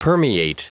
Prononciation du mot permeate en anglais (fichier audio)
Prononciation du mot : permeate